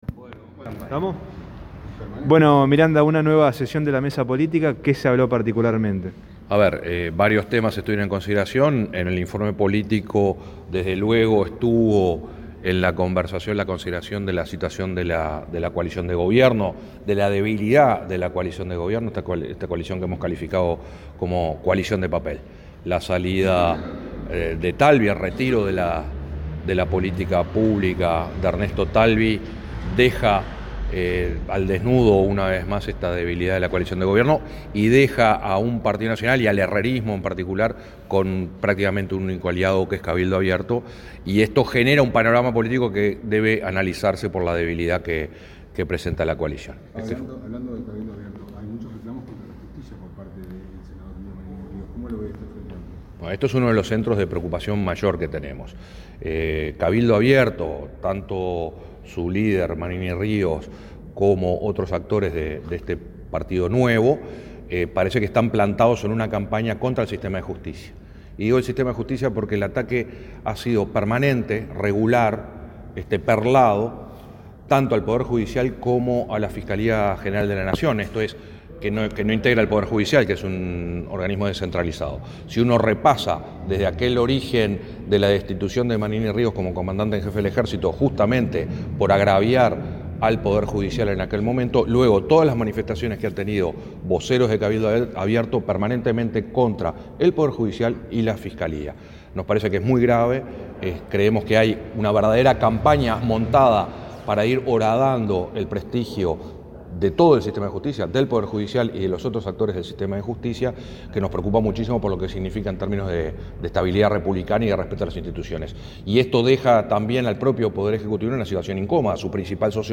Finalizada la Mesa Política, el presidente del Frente Amplio, Javier Miranda en declaraciones a la prensa expresó su preocupación por el «ataque permanente, regular y perlado al sistema de Justicia por parte de Manini Ríos y otros integrantes de Cabildo Abierto».